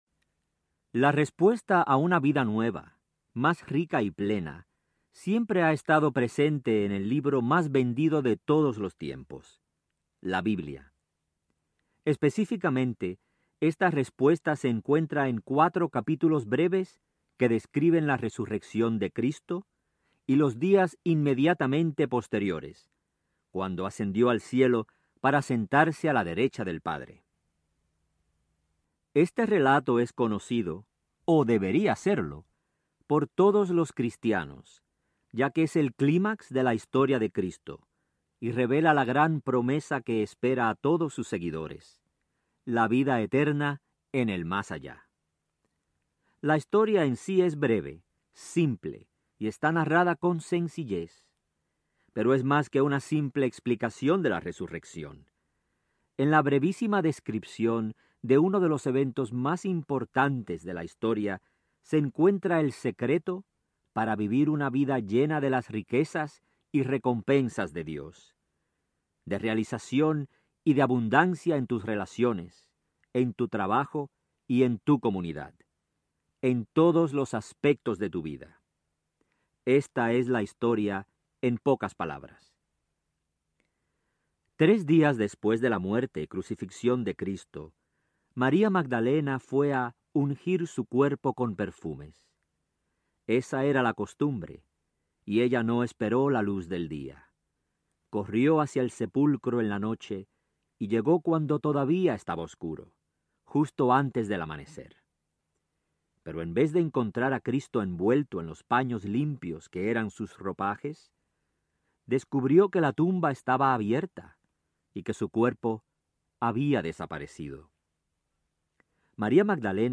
Camino de Milagros Audiobook
Narrator
9.7 Hrs. – Unabridged